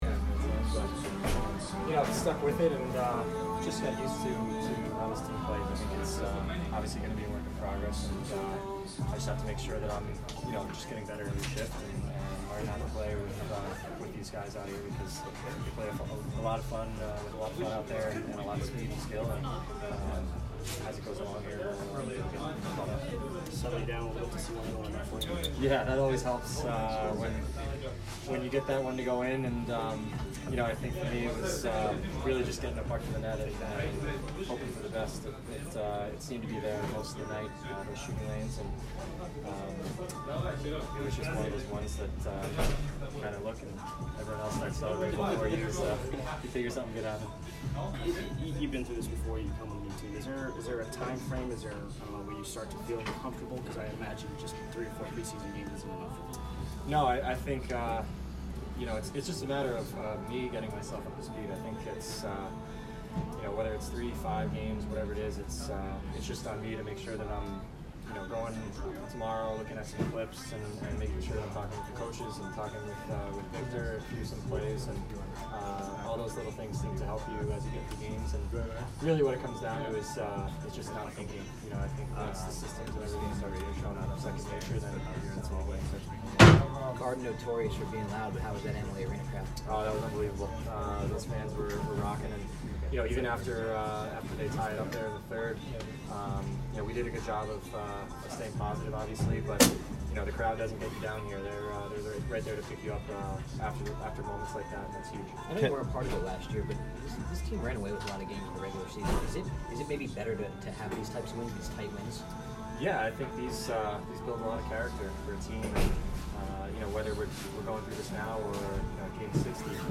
Kevin Shattenkirk post-game 10/3